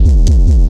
Bass 1 Shots (107).wav